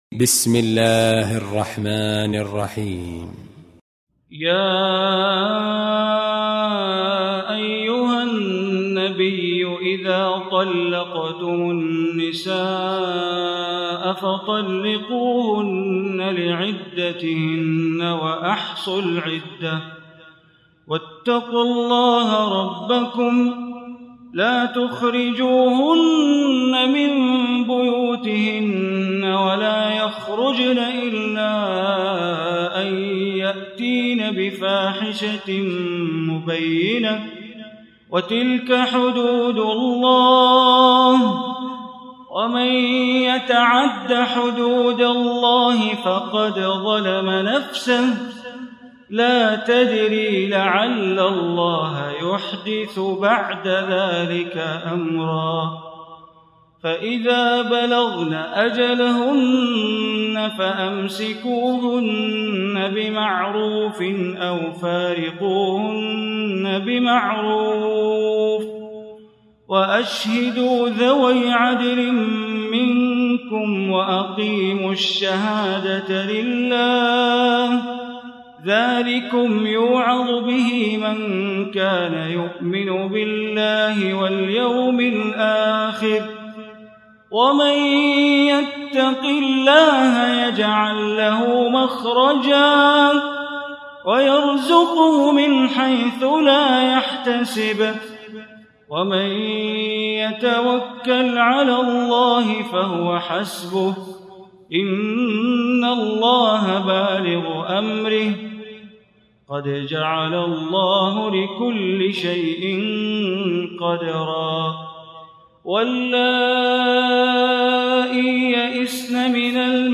Surah At-Talaq Recitation by Sheikh Bandar Baleela
65-surah-talaq.mp3